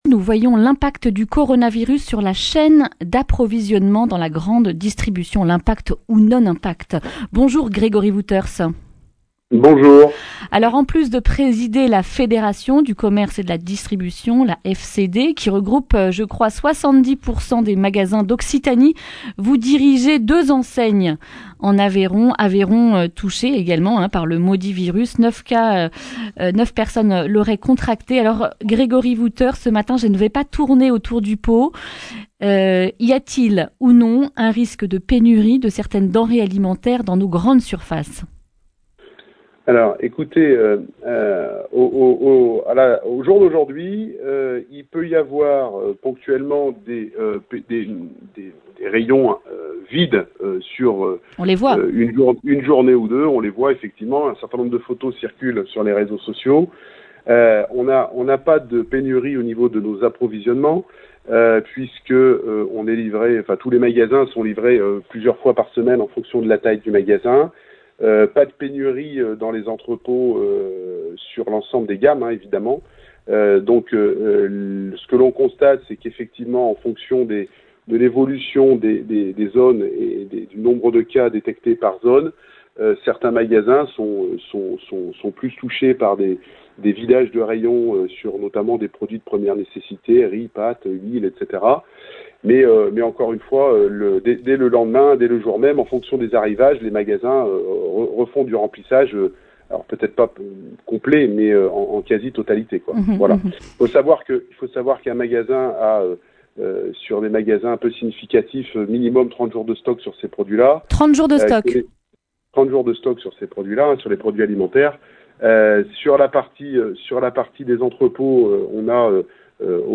jeudi 12 mars 2020 Le grand entretien Durée 10 min